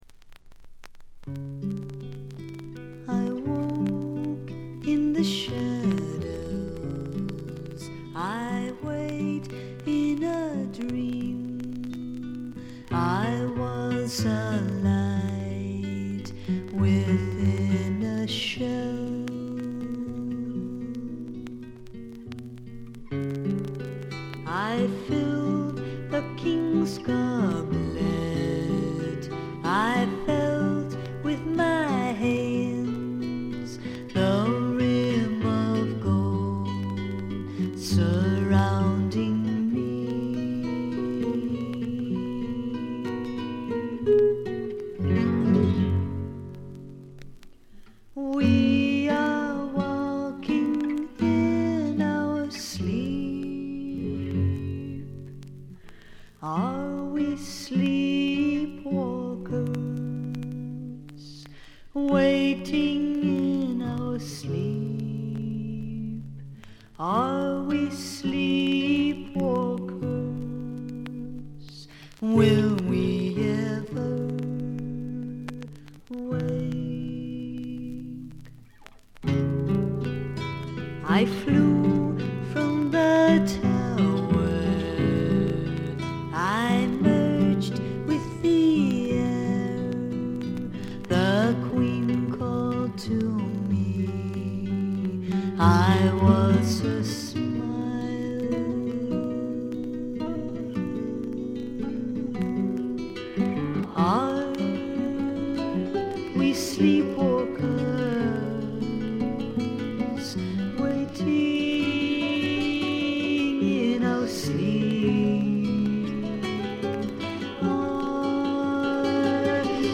常時大きめのバックグラウンドノイズが出ていますが、特に気になるようなノイズはありません。
スワンプナンバーでは強靭な喉を聴かせますが、アシッド路線では暗く妖艶で怪しいヴォーカルを響かせます。
試聴曲は現品からの取り込み音源です。